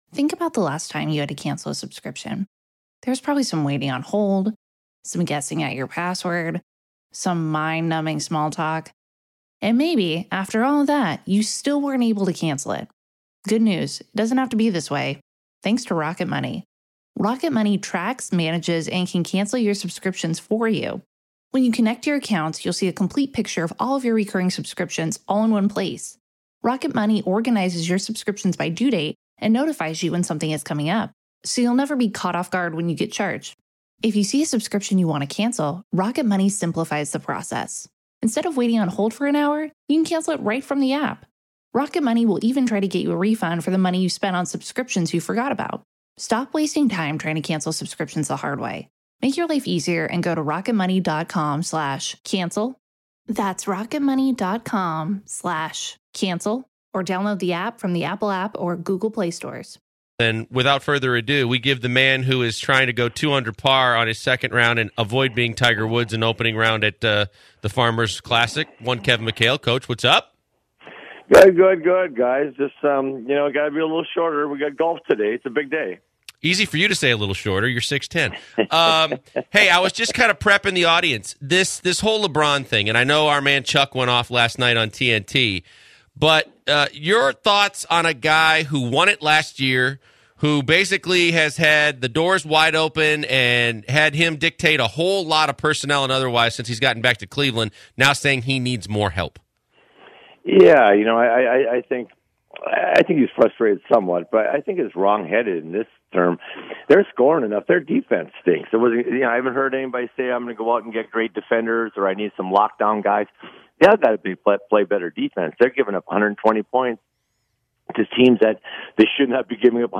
01/27/2017 Kevin McHale Interview.
Kevin McHale calls in to talk abot leBron James and his comments after the recent cavs struggles, plus they go over the Houston Rockets and on the final topic, Kevin talks about the Chicago Bulls and their debacle with Jimmy Butler and Dwayne Wade.